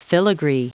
Prononciation du mot filigree en anglais (fichier audio)
Prononciation du mot : filigree